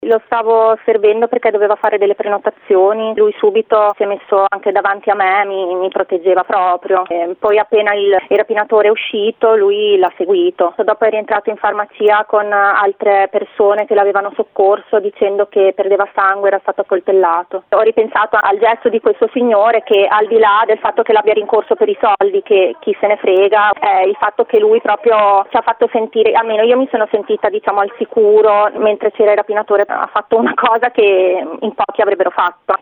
Sentiamo il racconto di una delle farmaciste: